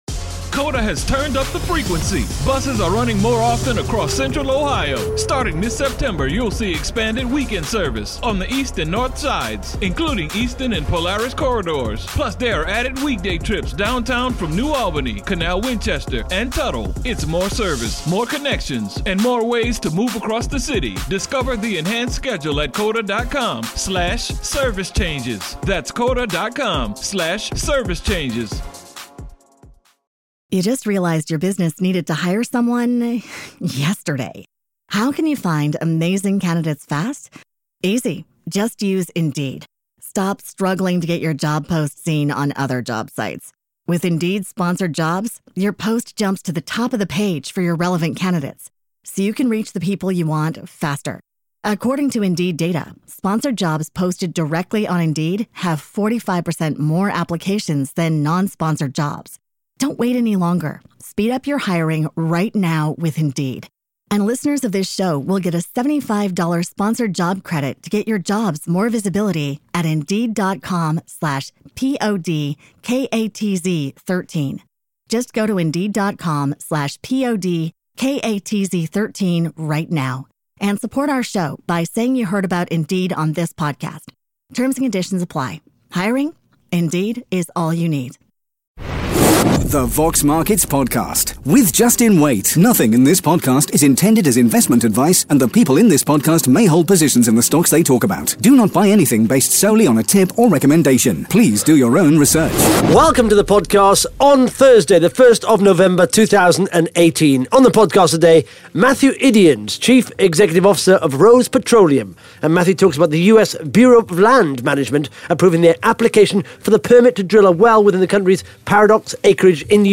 (Interview starts at 2 minutes)